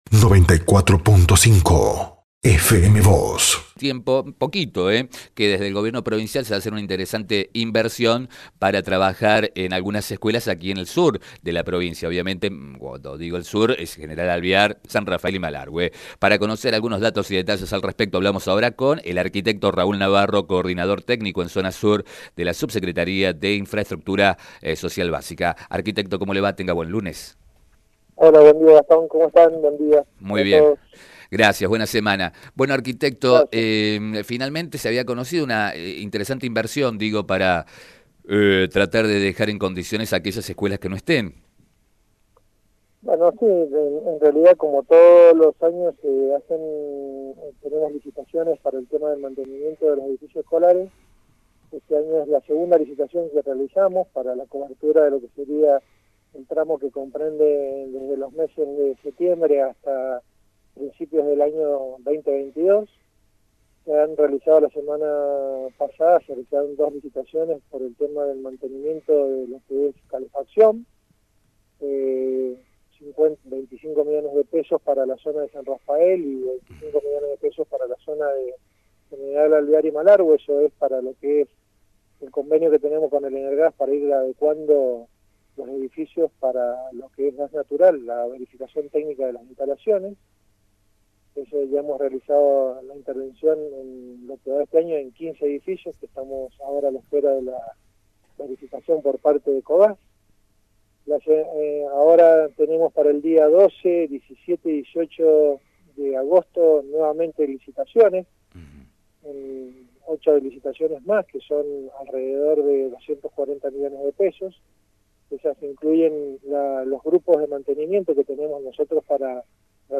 “Como todos los años se hacen licitaciones para mantenimiento de edificios escolares, este año se hizo una licitación para la cobertura del tramo que comprende desde septiembre hasta principios del año próximo y es para calefacción. En total serán 25 millones de pesos para San Rafael y otros 25 para Malargüe y General Alvear”, indicó a FM Vos (94.5).